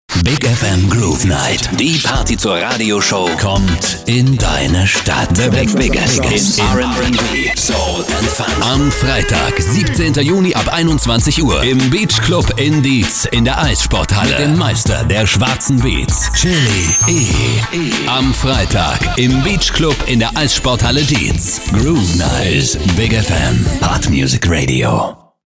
The Biggest Sounds in RnB, Soul and Funk